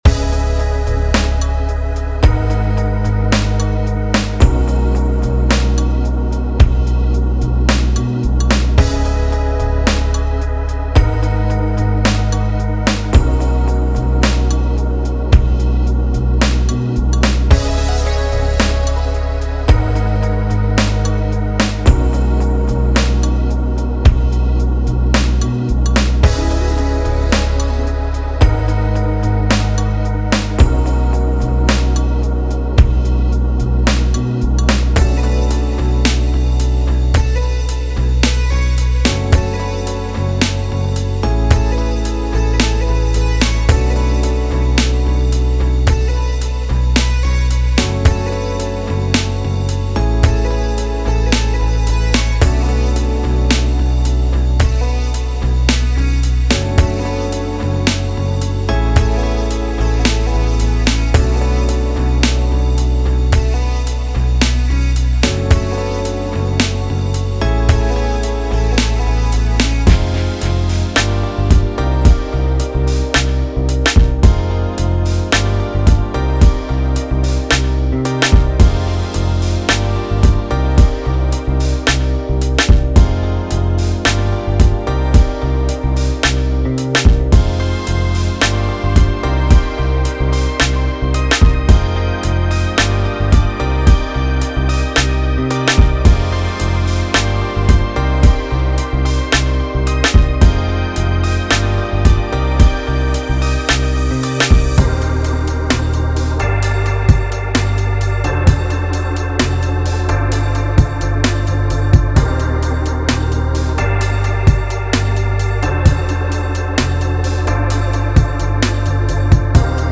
With a total of 90 loops, this pack offers an eclectic mix of bass, FX, drums, synth, and vocal loops, providing you with all the elements you need to create compelling and unique music.
Bass Loops: 20 deep and groovy basslines to lay down the perfect foundation for your tracks.
FX Loops: 15 atmospheric and dynamic effects to add depth and character to your compositions.
Drum Loops: 25 intricate and rhythmic drum patterns, ranging from laid-back grooves to uptempo beats, ensuring you find the right rhythm for any project.
Synth Loops: 20 lush and innovative synth loops that blend traditional jazz elements with modern electronic sounds, perfect for adding melodic richness.
Vox Loops: 10 soulful and expressive vocal loops to bring a human touch and emotional depth to your music.
High-Quality Audio: Professionally recorded and mixed, ensuring pristine sound quality for your projects.